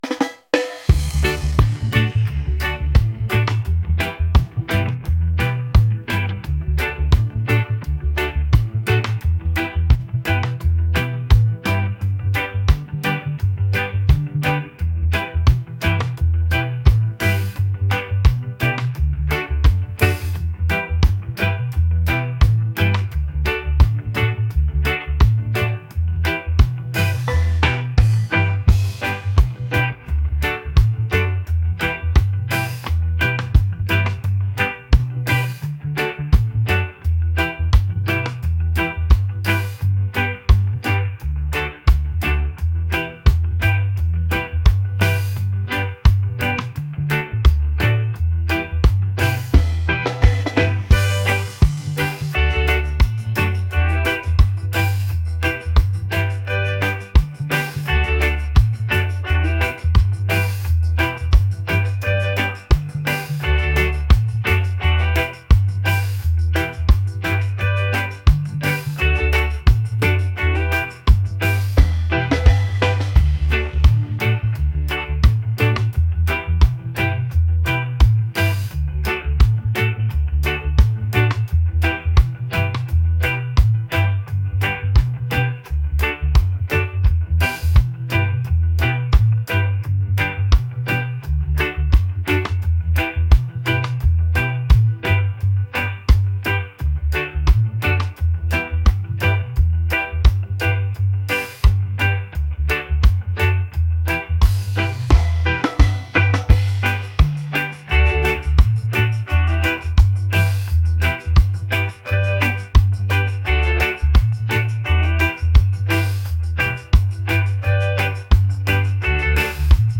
soulful | reggae